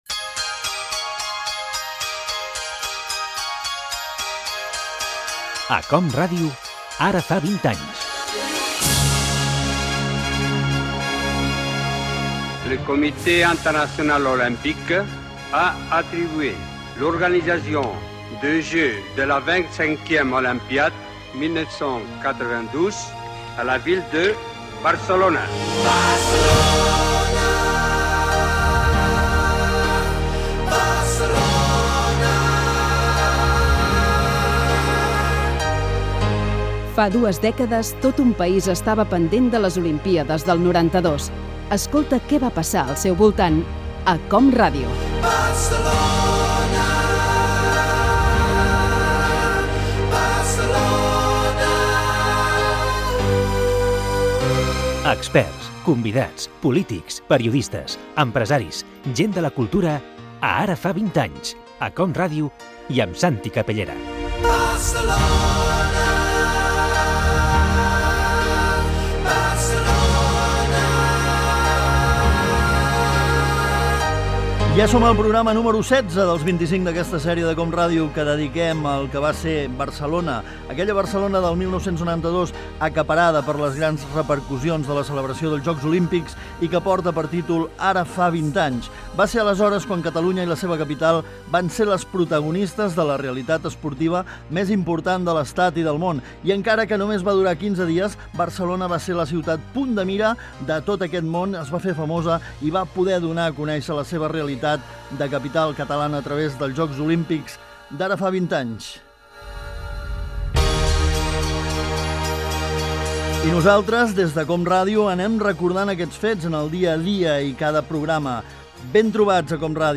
Fragment de la sèrie de programes dedicats als Jocs Olímpics de Barcelona de l'any 1992. Careta del programa i presentació de l'episodi 16
Presentador/a